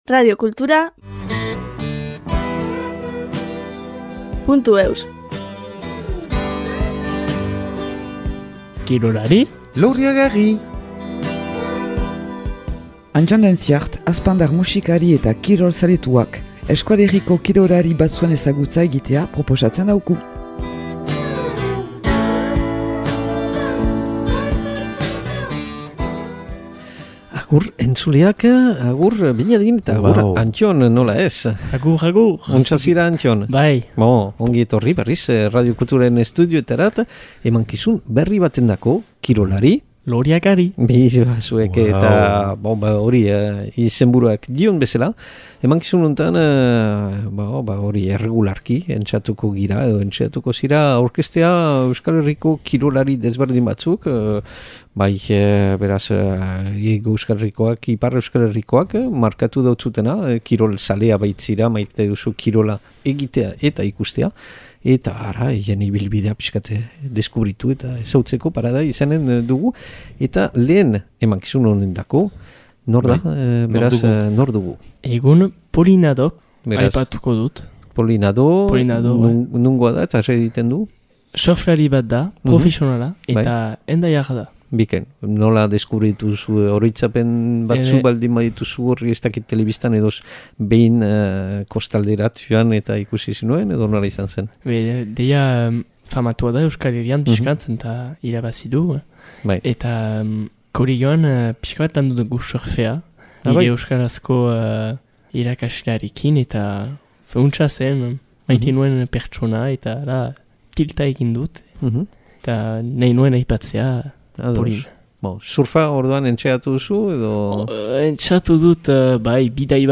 (Musika edo kantu zati pare bat deskubritzea proposatzen duen bitartean).